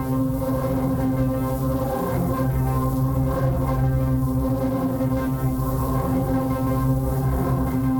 Index of /musicradar/dystopian-drone-samples/Tempo Loops/90bpm
DD_TempoDroneC_90-C.wav